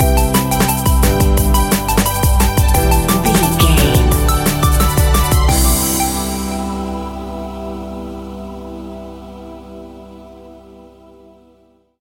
Ionian/Major
Fast
groovy
uplifting
bouncy
futuristic
drums
synthesiser
electronic
sub bass
synth leads